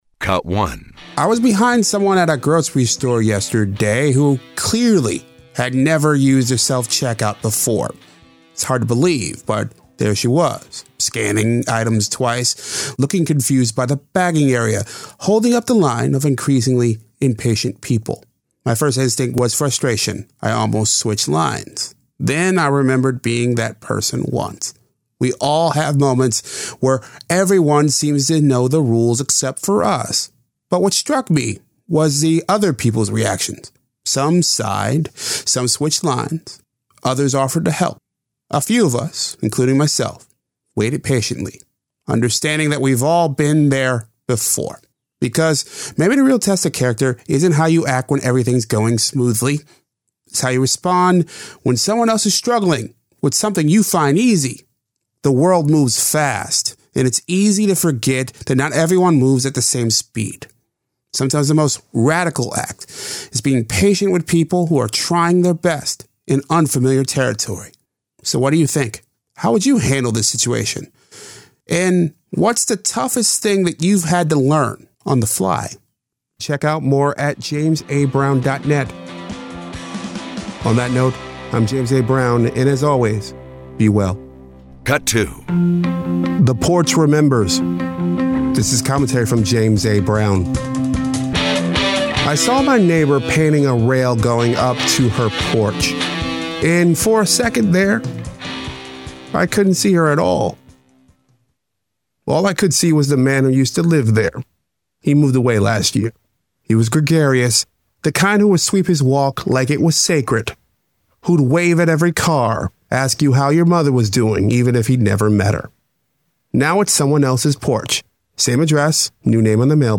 • THE DAILY NOTE is the :60 feature that hits the sweet spot -- real talk, real life, every day.